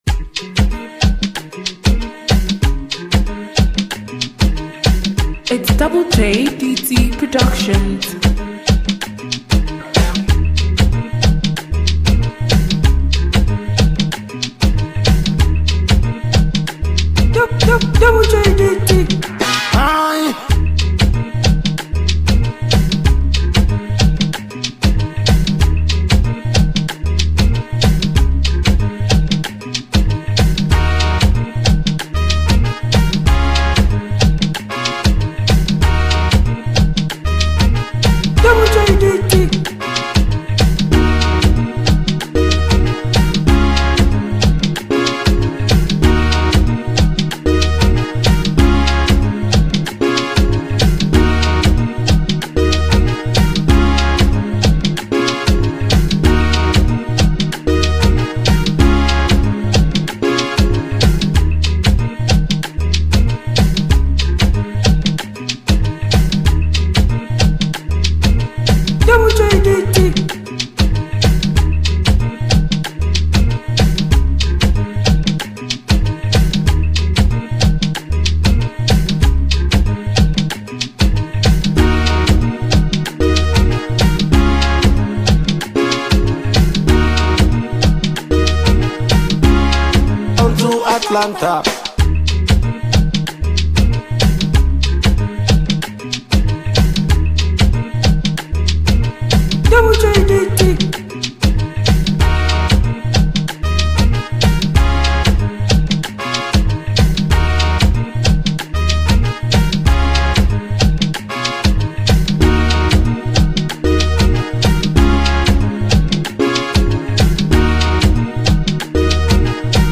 official remake
Download and make perfect music with this freestyle beat.